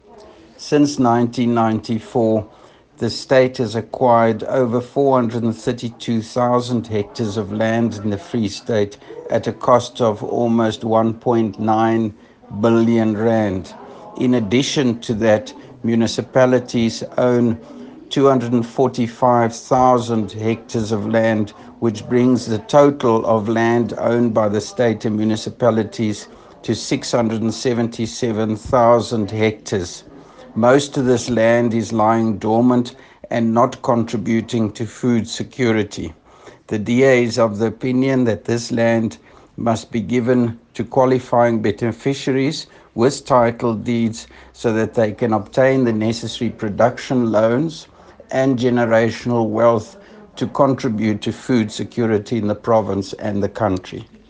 English and Afrikaans soundbites by Roy Jankielsohn MPL